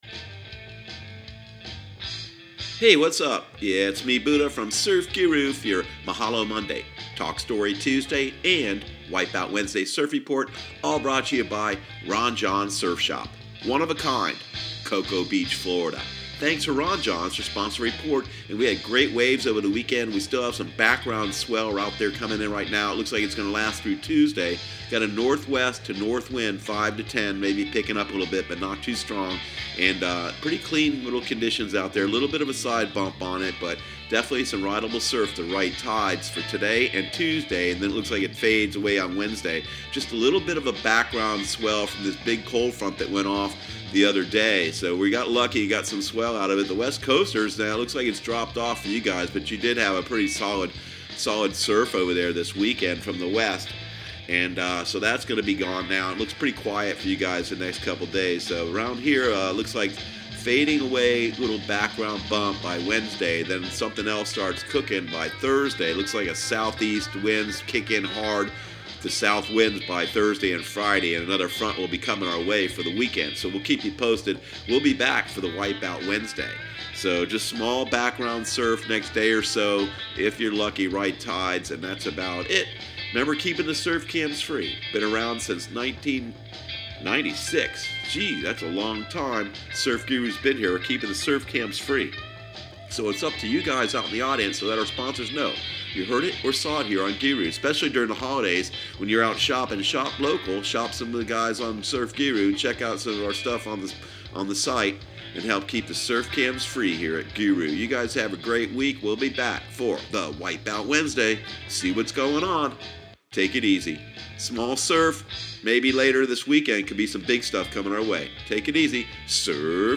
Surf Guru Surf Report and Forecast 12/17/2018 Audio surf report and surf forecast on December 17 for Central Florida and the Southeast.